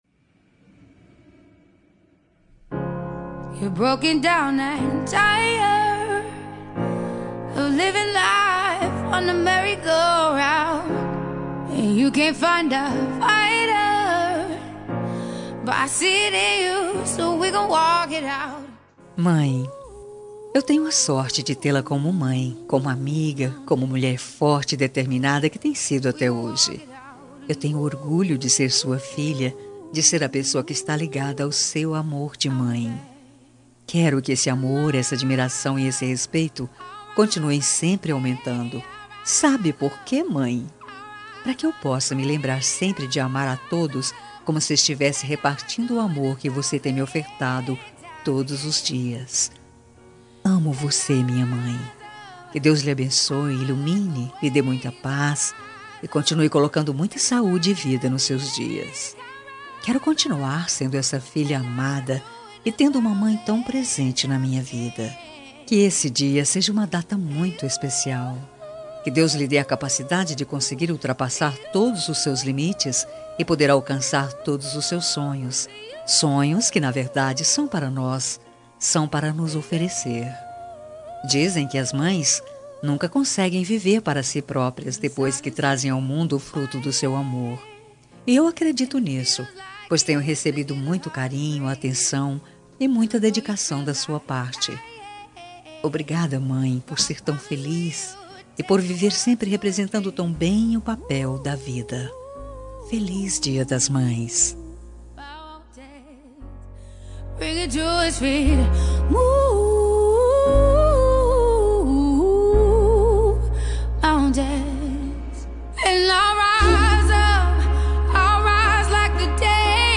Dia das Mães – Para minha Mãe – Voz Feminina – Cód: 6502